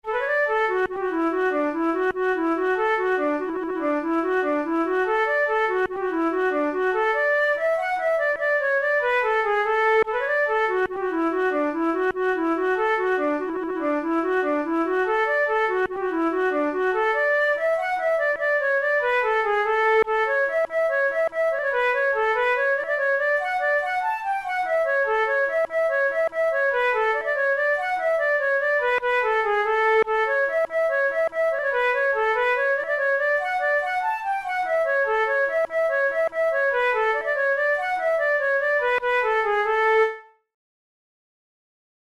Traditional Irish jig
Categories: Jigs Traditional/Folk Difficulty: easy